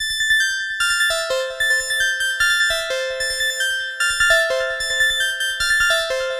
Tag: 150 bpm Electronic Loops Synth Loops 1.08 MB wav Key : Unknown FL Studio